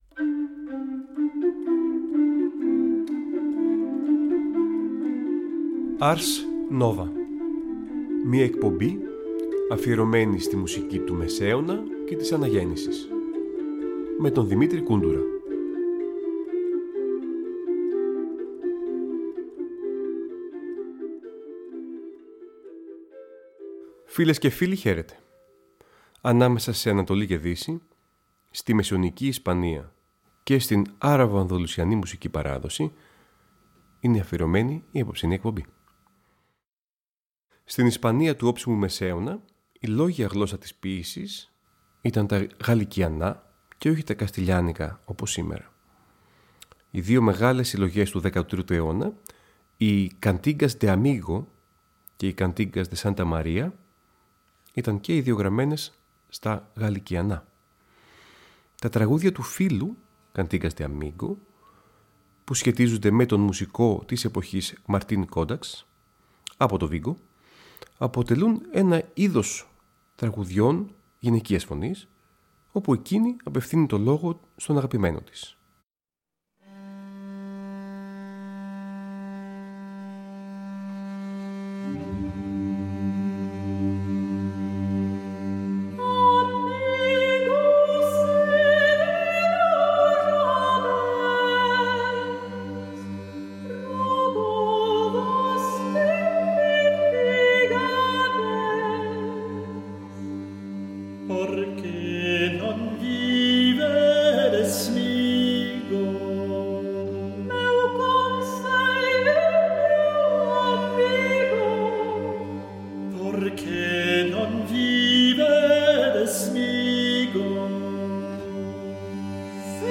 Νέα ωριαία μουσική εκπομπή του Τρίτου Προγράμματος που μεταδίδεται κάθε Τρίτη στις 19:00.